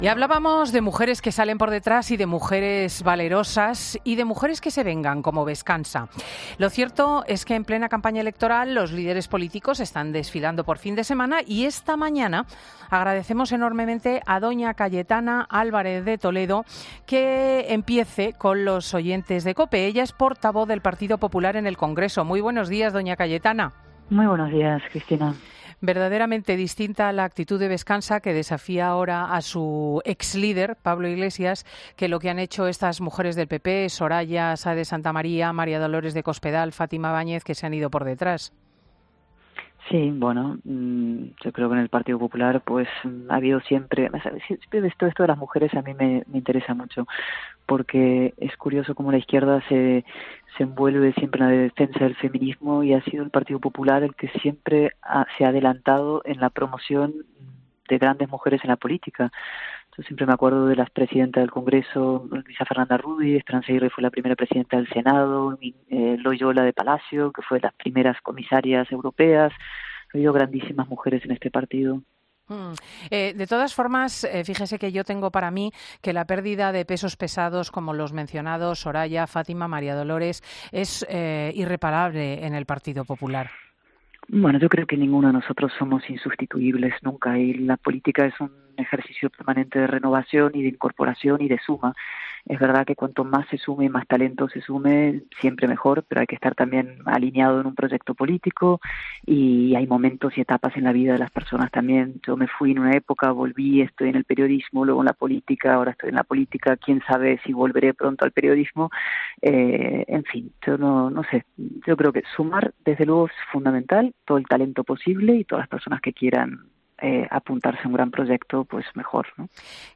La portavoz del Partido Popular en el Congreso ha pasado por los micrófonos de ‘Fin de Semana’ con Cristina López Schlichting y ha asegurado que “ es insuficiente un solo debate , según todas las encuestas, hay dos grandes fuerzas políticas con capacidad de gobernar, el PSOE y el PP, y por eso tiene que haber un cara a cara”.